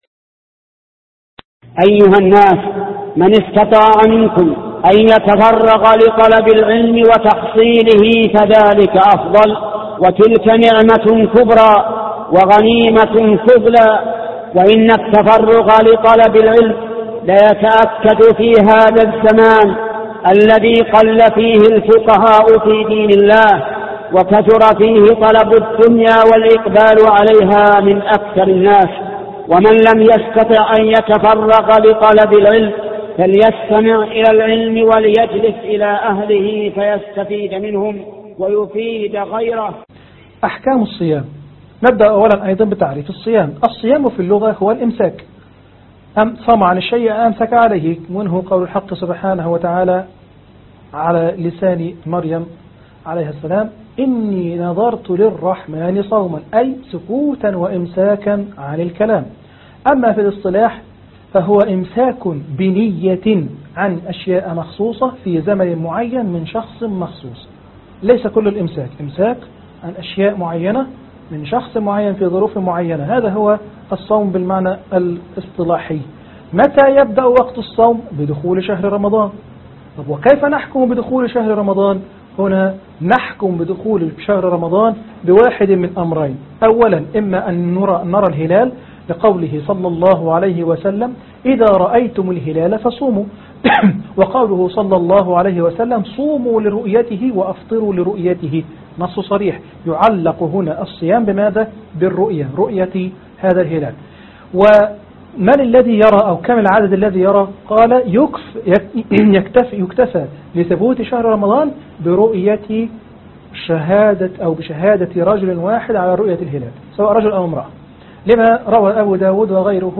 محاضرة 10 - احكام الصيام(الفقه المستوى التمهيدي الأكاديمية الإسلامية العلمية)